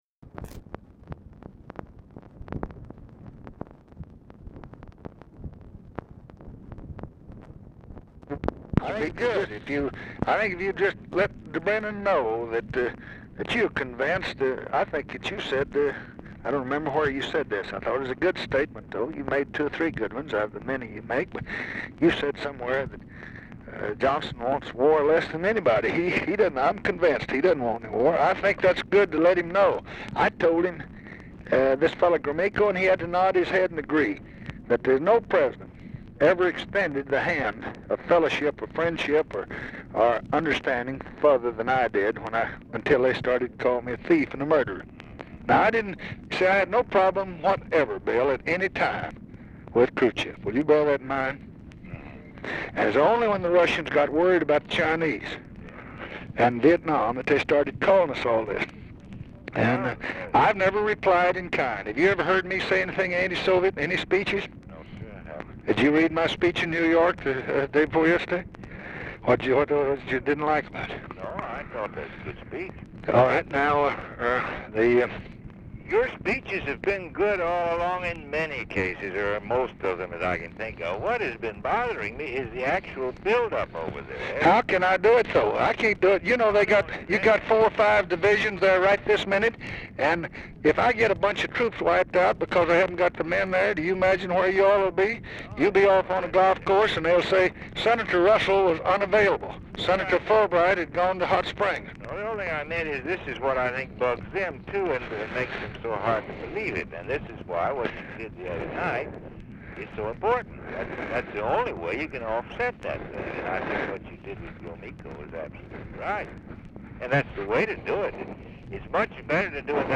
Telephone conversation # 10943, sound recording, LBJ and WILLIAM FULBRIGHT, 10/11/1966, 5:20PM | Discover LBJ
Format Dictation belt
Location Of Speaker 1 Mansion, White House, Washington, DC
Specific Item Type Telephone conversation